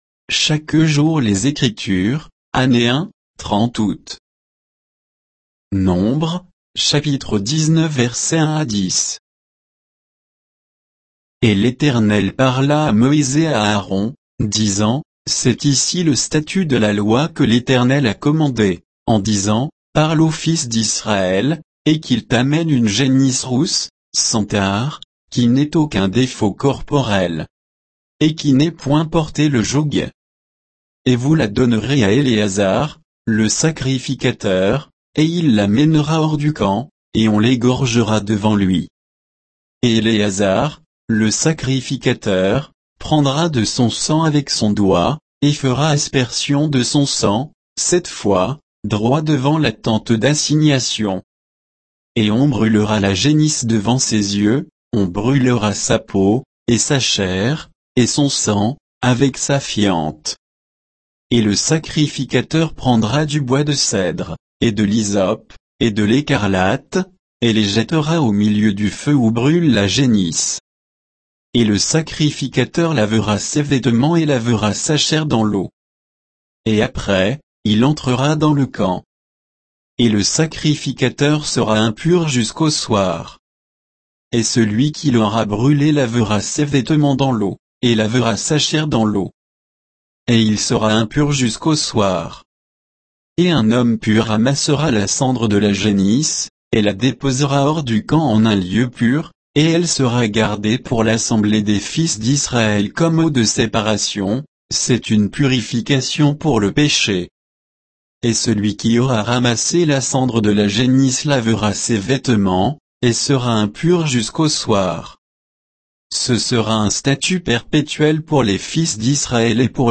Méditation quoditienne de Chaque jour les Écritures sur Nombres 19